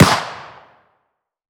CookoffSounds / shotshell / close_1.wav
Cookoff - Improve ammo detonation sounds